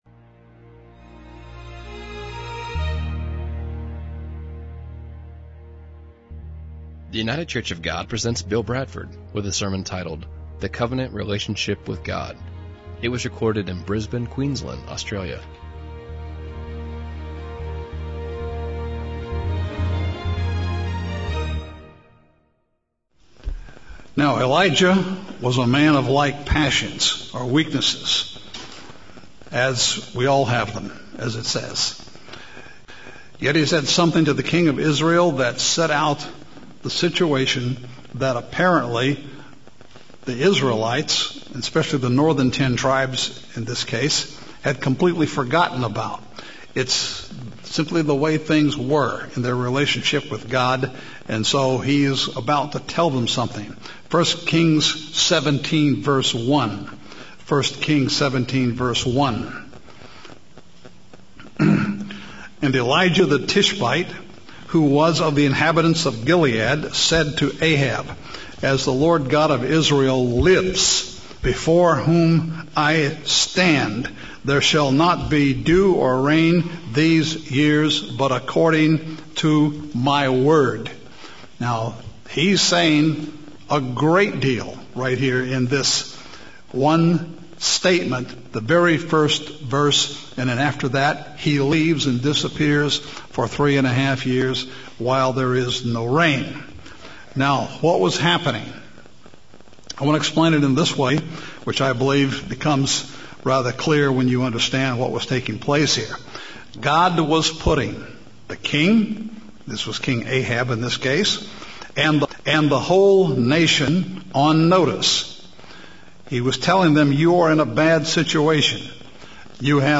Sermon
2013 in the Brisbane, Queensland, Australia congregation.